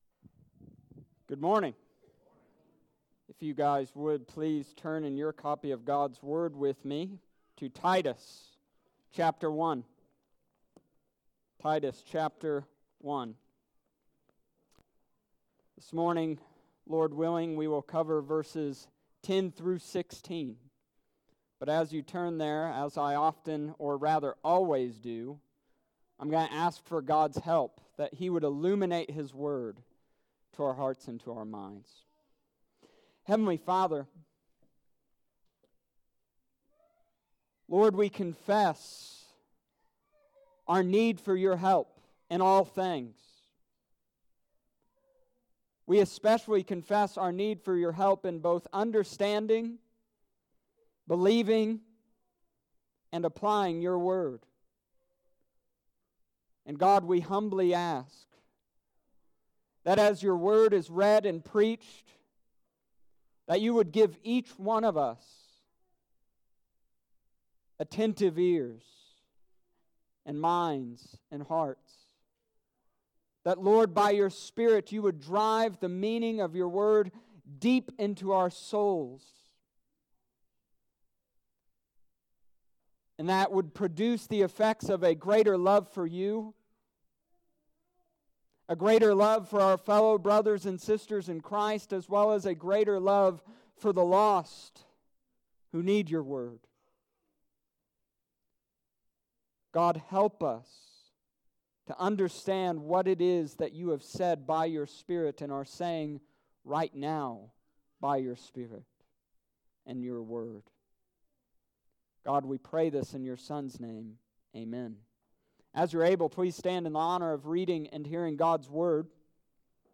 Summary Of Sermon: This week, we continued the book of Titus.